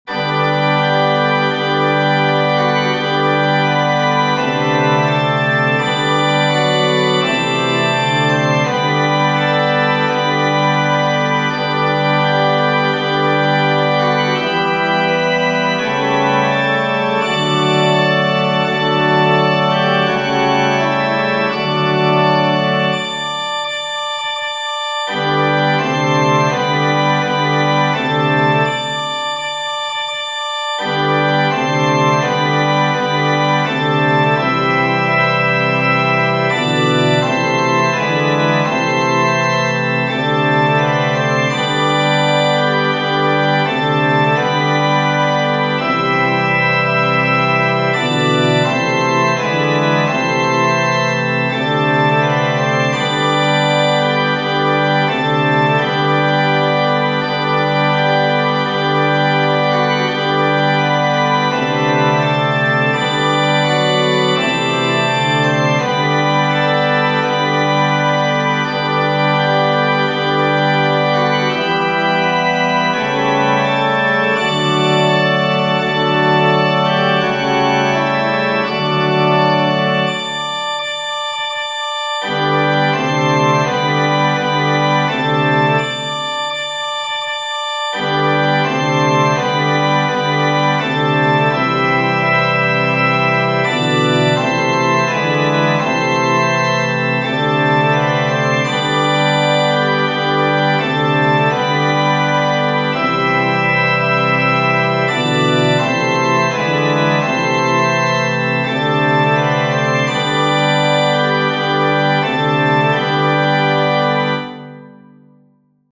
Genere: Religiose